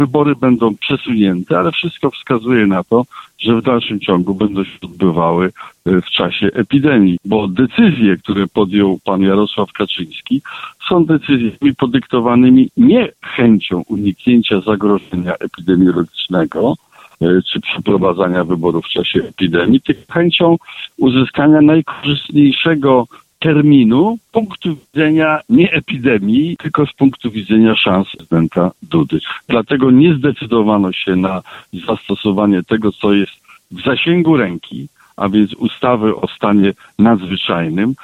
Bronisław Komorowski komentował decyzje dotyczące głosowania dziś (07.05), podczas audycji „Gość Radia 5”.